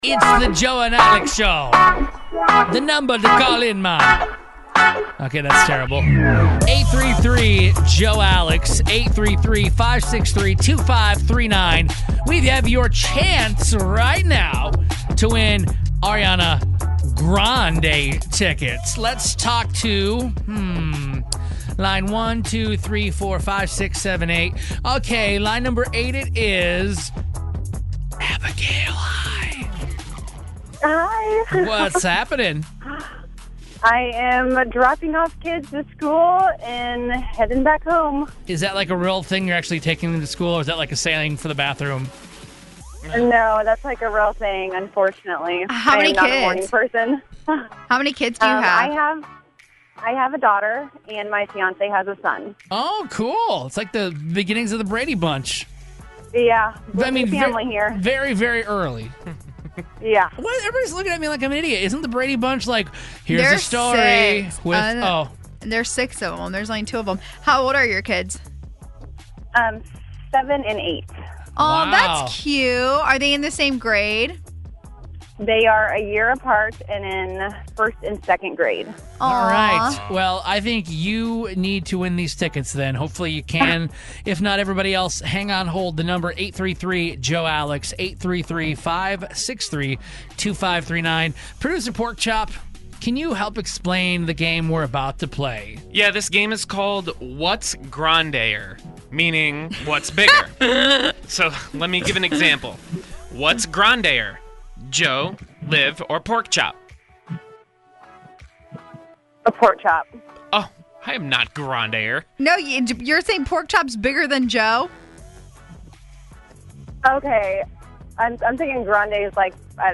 We asked you whats Grande'er (bigger). We had a caller make it to the last question!